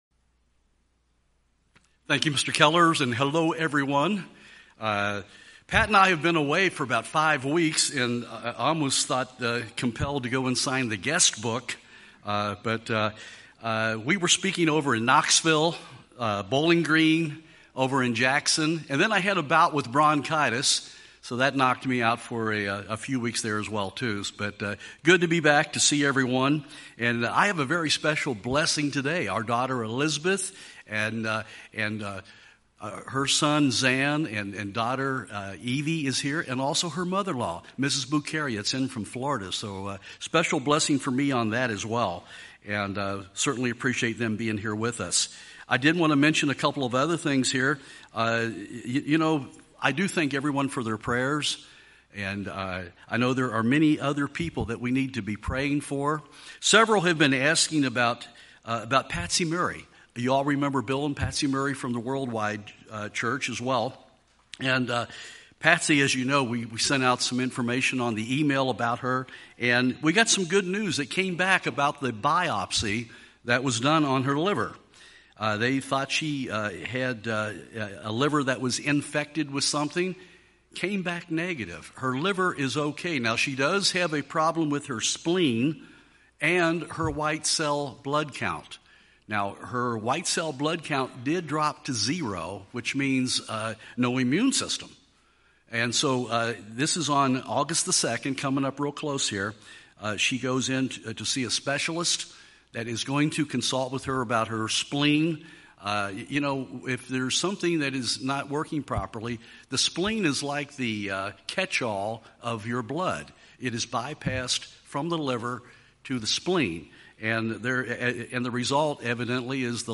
This sermon was given at the Cincinnati, Ohio 2016 Feast site.